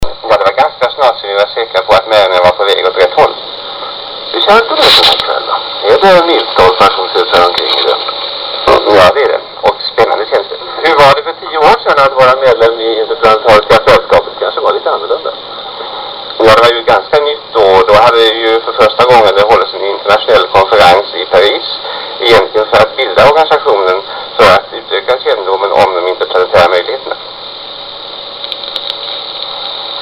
(Dålig ljudkvalitet i början).